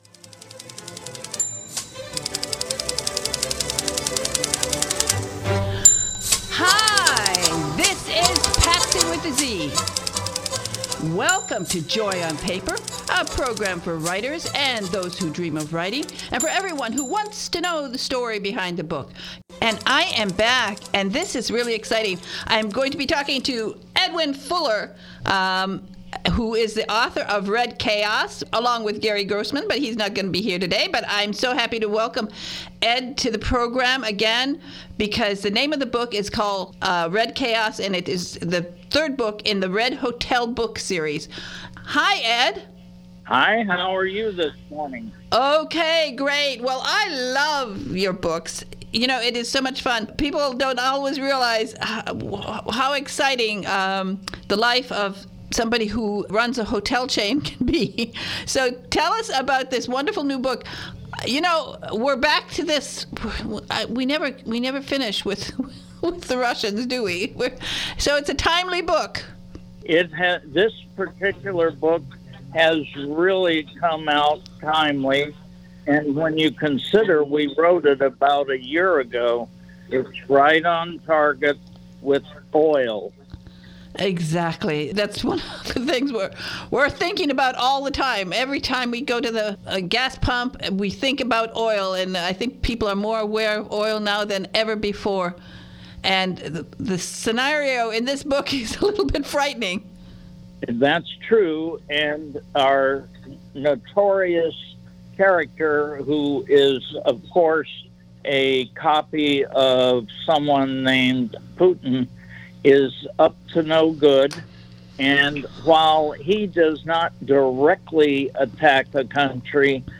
This is the first interview today